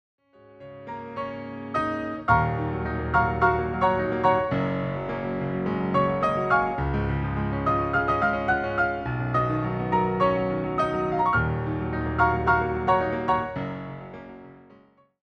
translate to a solo piano setting.